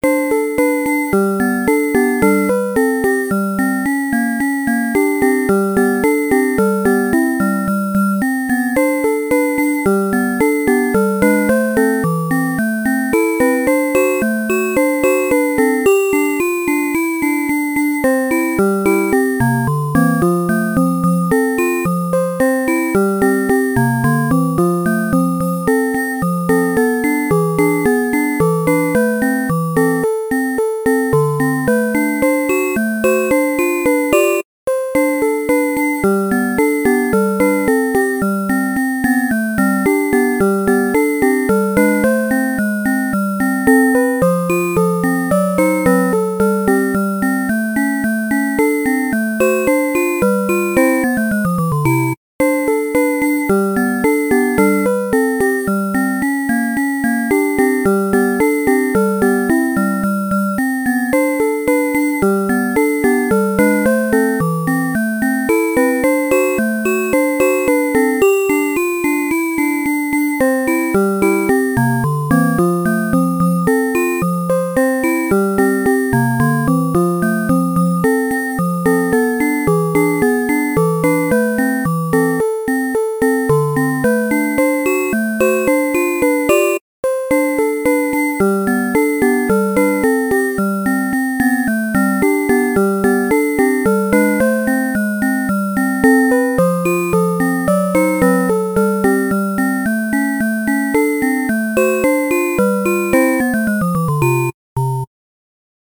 SVM7570 remix